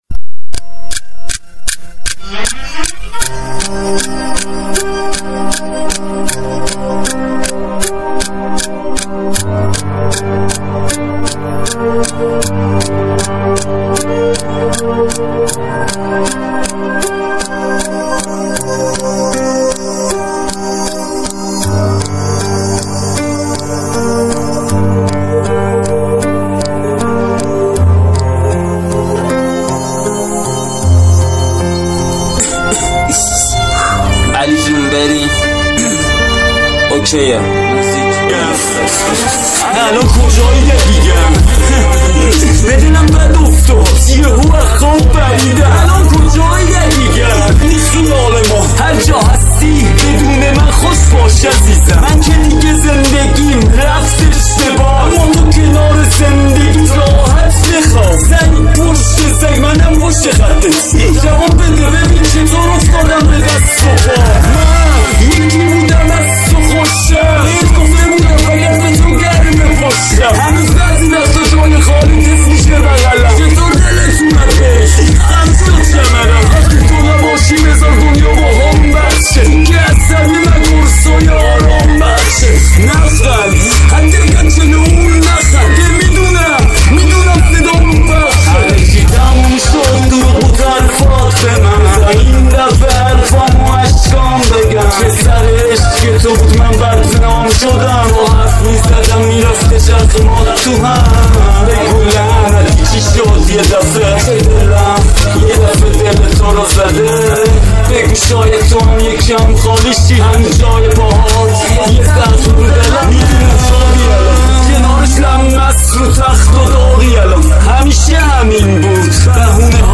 Genre Rap